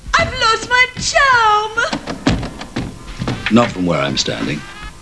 Here are a few sound bites from the movie: